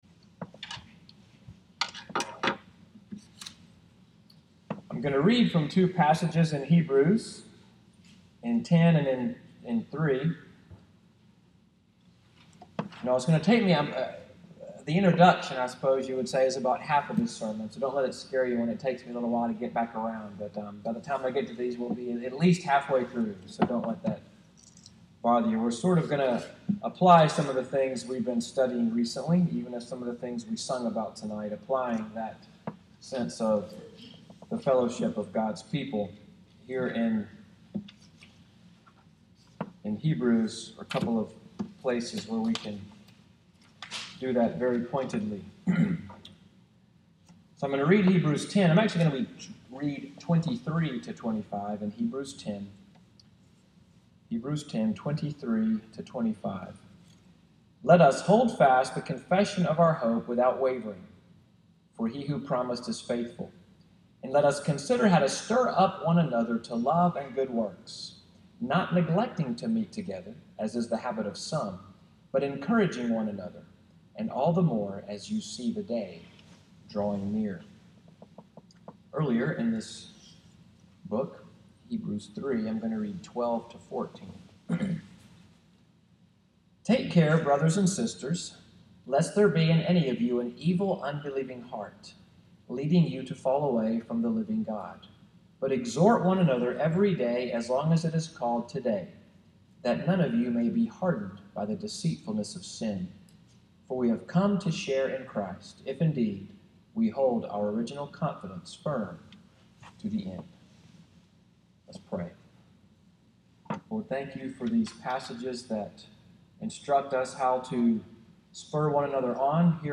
Sermon texts: Hebrews 10:24-25 & 3:12-13